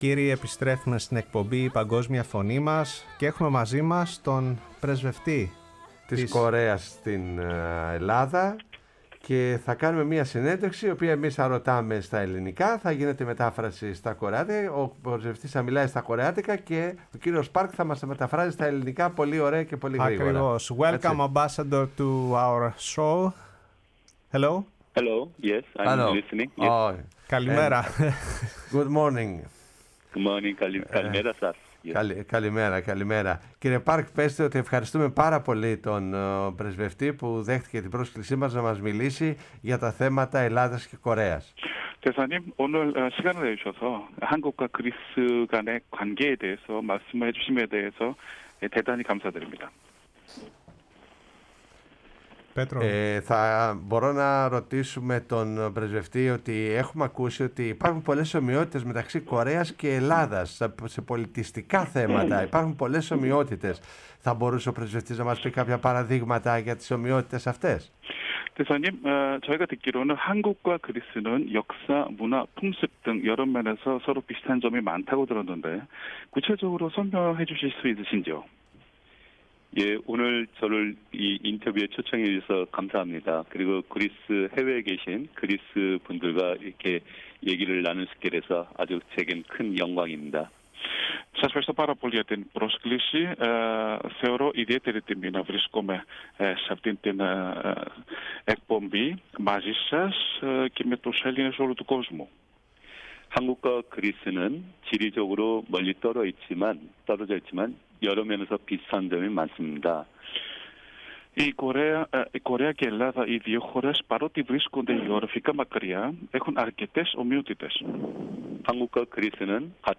Για τoυς δεσμούς που συνδέουν την Ελλάδα με τη Νότιο Κορέα από την αποστολή ελληνικού εκστρατευτικού σώματος στο πόλεμο της Κορέας την δεκαετία του 1950, τις στενές σχέσεις στη ναυτιλία και την ναυπηγική και την αντιμετώπιση της πανδημίας μίλησε στην εκπομπή «Η Παγκόσμια Φωνή μας» στο Ραδιόφωνο της Φωνής της Ελλάδας ο πρεσβευτής της Νοτίου Κορέας στην Ελλάδα κ. Λιμ..